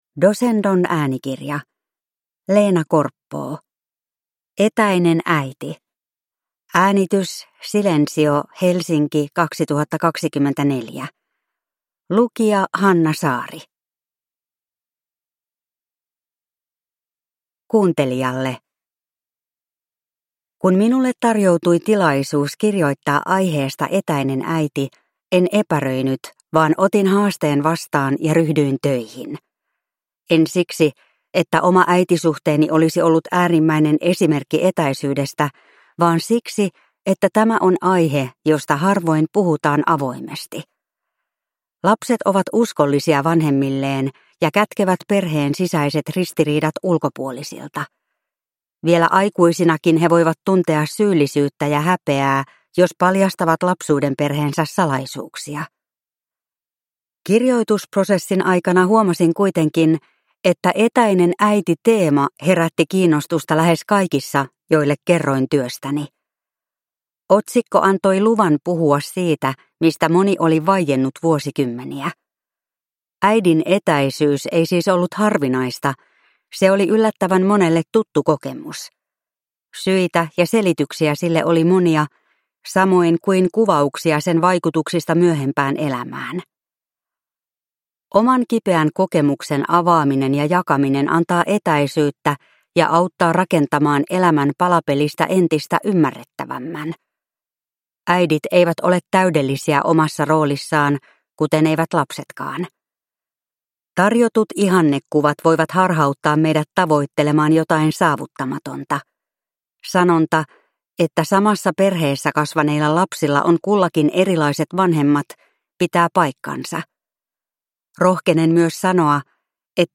Etäinen äiti – Ljudbok